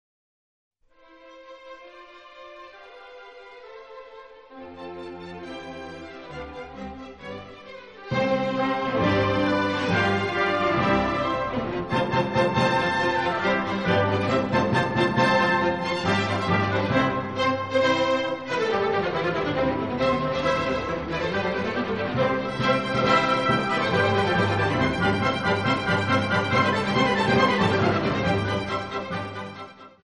Mozart - Symphony in C Major (The Jupiter Symphony) - IV Molto Allegro